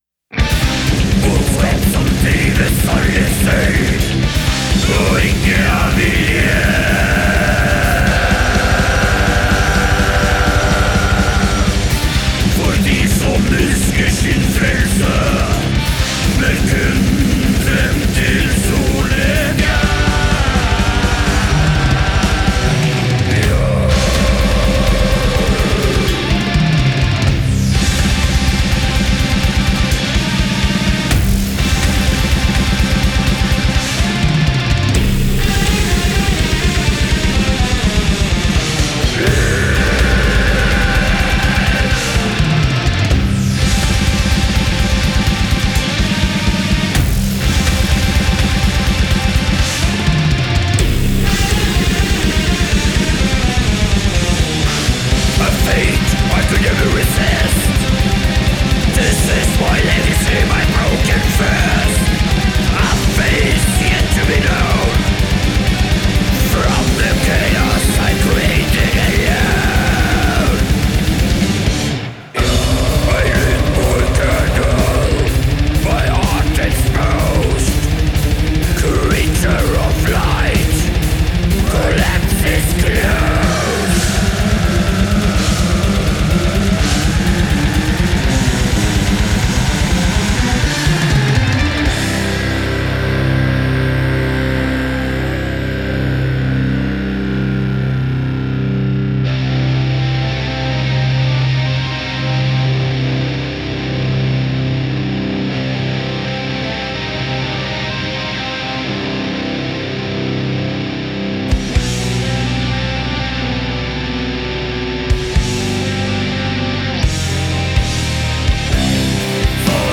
genre: avant-garde black metal year: 2023